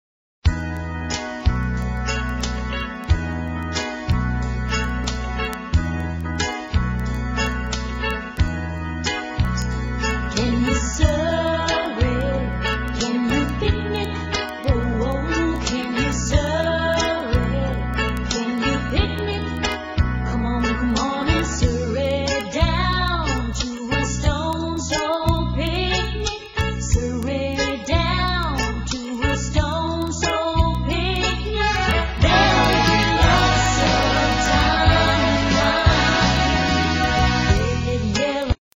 NOTE: Vocal Tracks 1 Thru 6